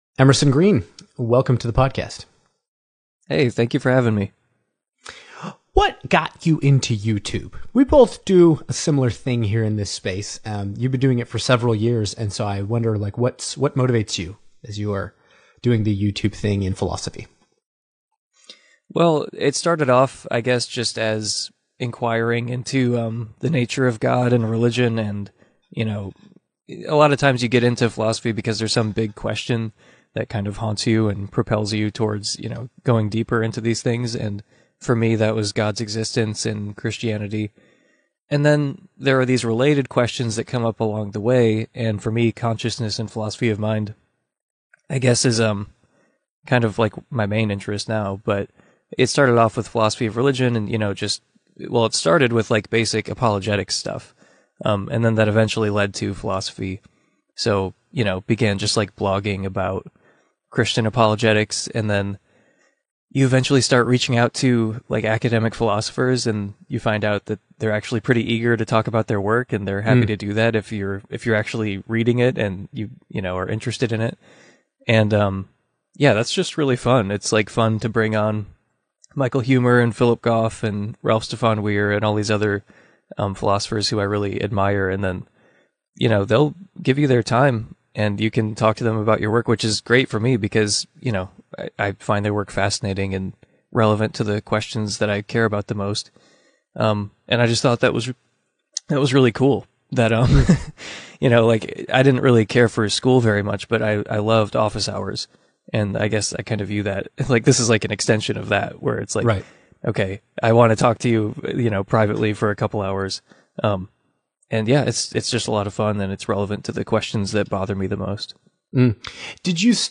Young Apologists Interview - Atheism, Hell, Aliens, Mormonism, and the Simulation Hypothesis